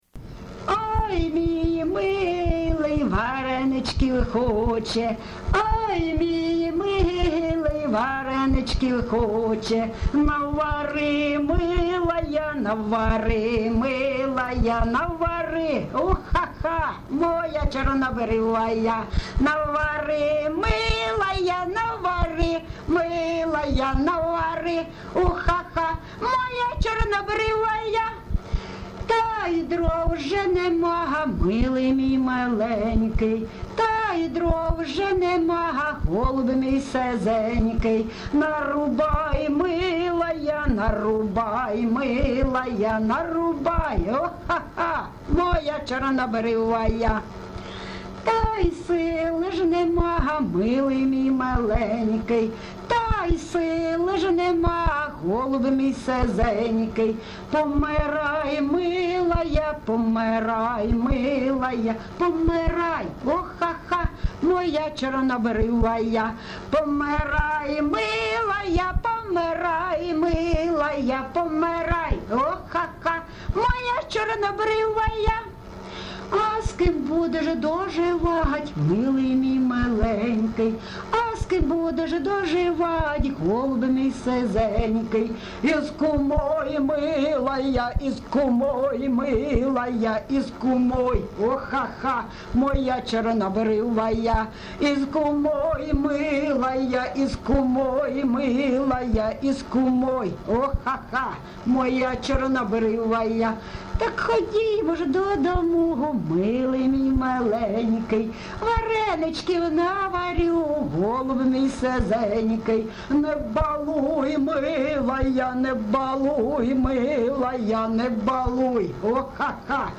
ЖанрЖартівливі
Місце записус. Лозовівка, Старобільський район, Луганська обл., Україна, Слобожанщина